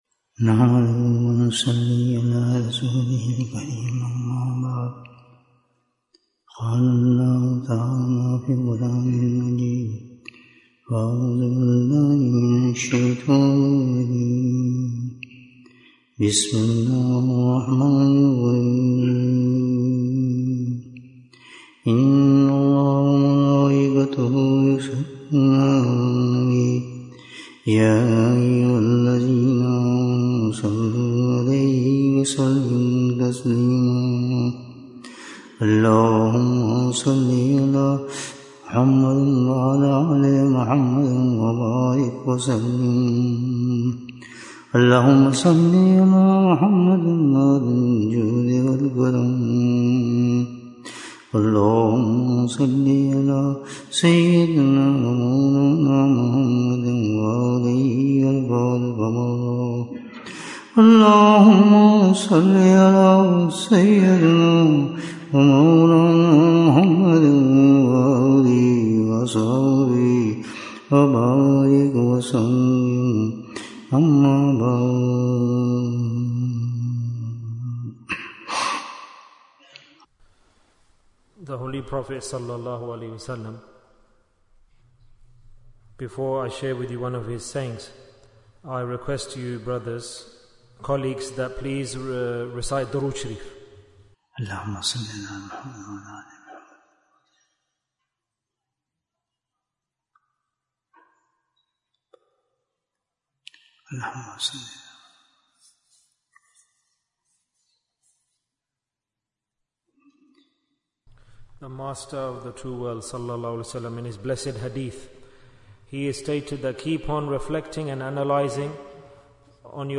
Easy Way to Leave Sins Bayan, 104 minutes23rd January, 2025